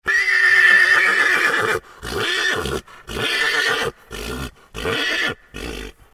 جلوه های صوتی
دانلود صدای اسب 8 از ساعد نیوز با لینک مستقیم و کیفیت بالا